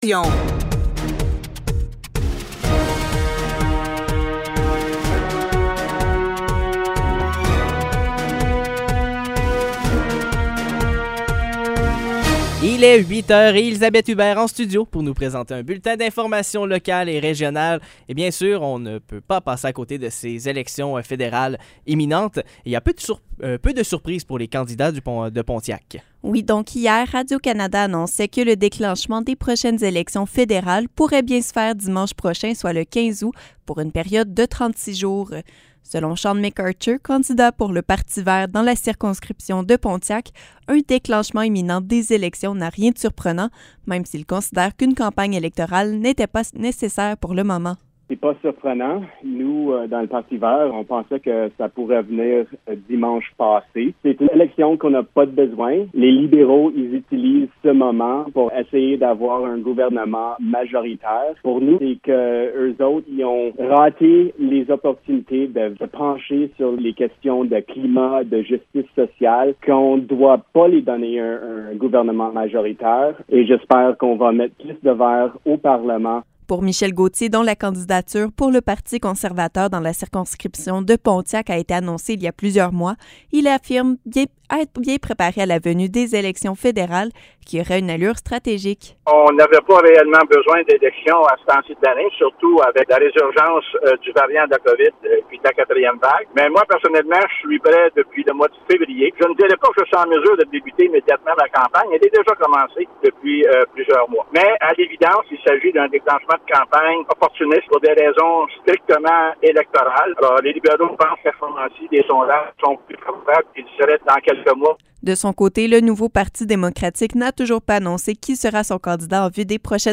Nouvelles locales - 13 août 2021 - 8 h